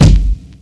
KICK - ASKER.wav